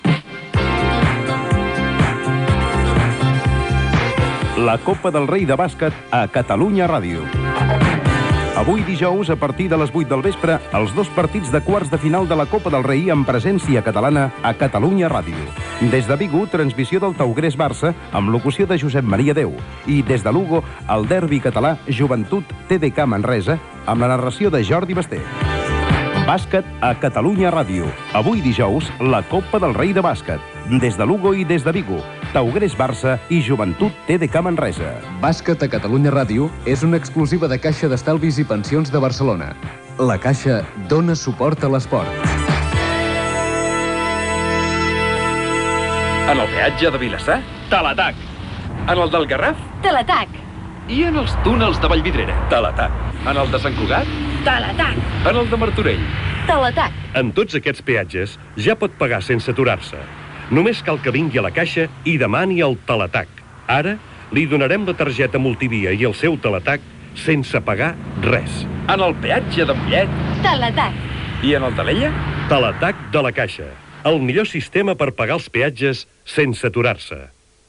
Promoció de la transmissio de dos partits dels quarts de final de la copa del Rei de Bàsquet, publicitat